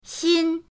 xin1.mp3